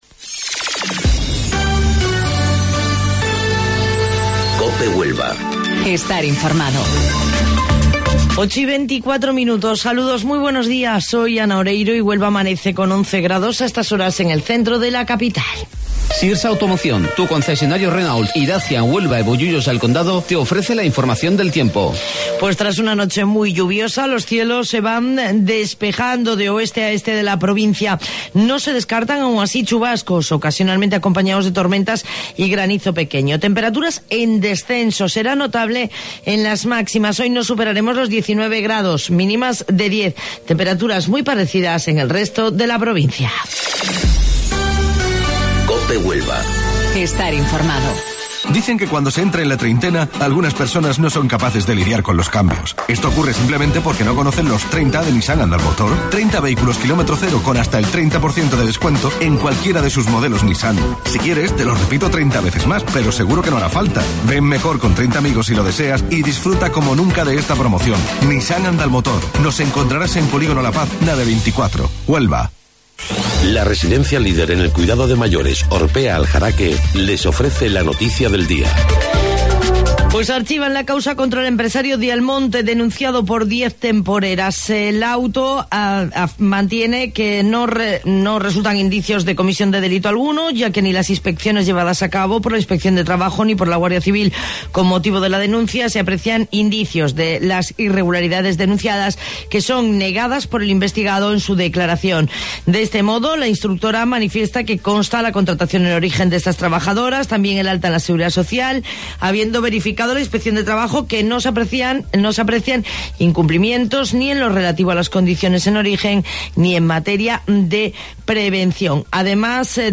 AUDIO: Informativo Local 08:25 del 23 de Abril